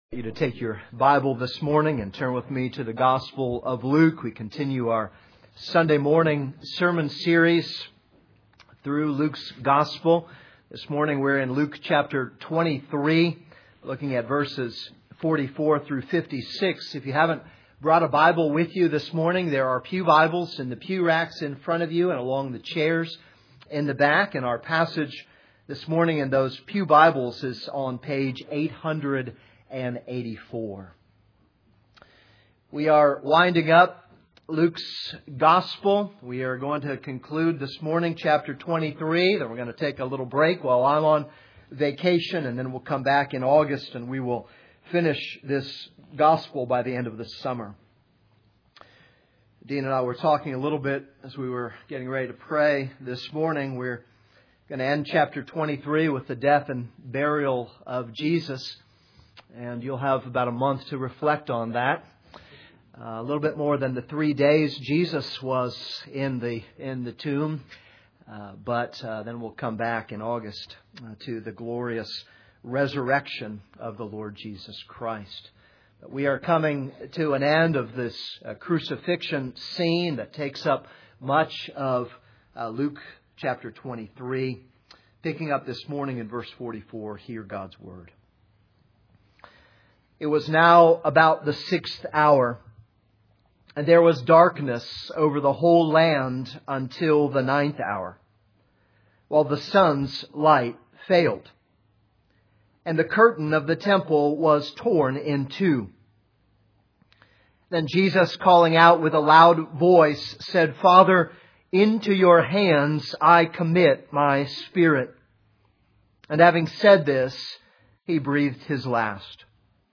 This is a sermon on Luke 23:44-56.